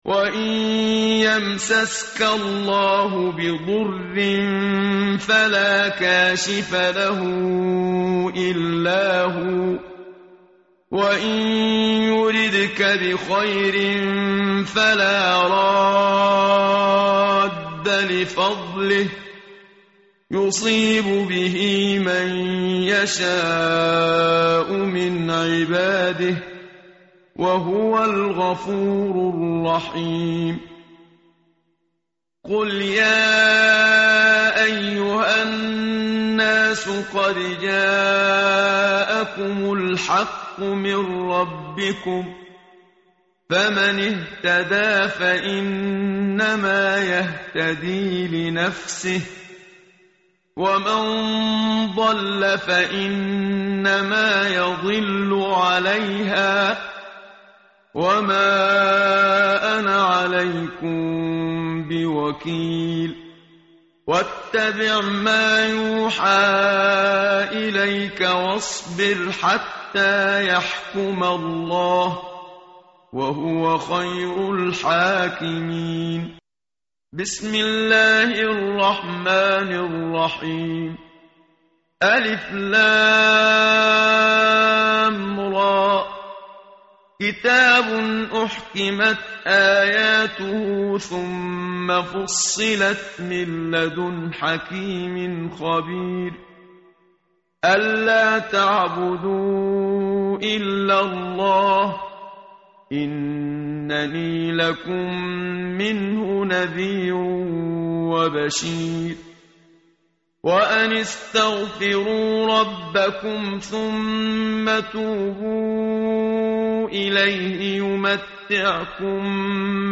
tartil_menshavi_page_221.mp3